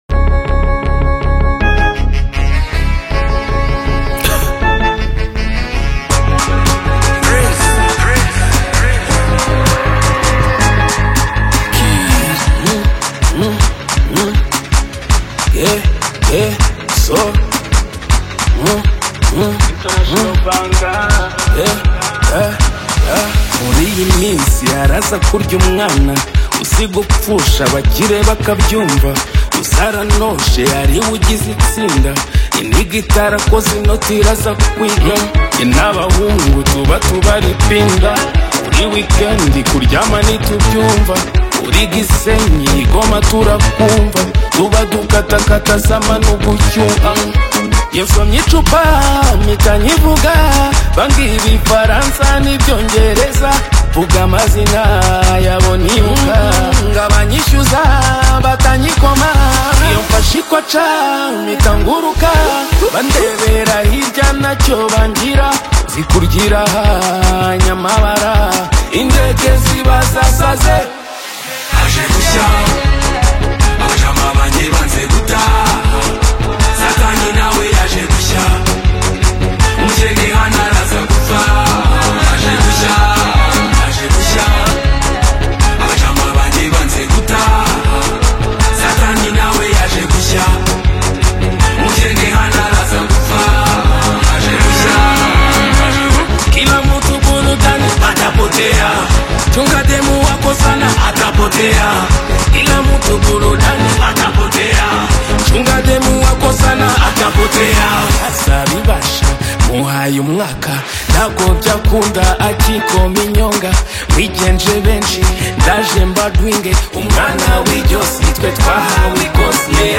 Through heartfelt lyrics and a soothing rhythm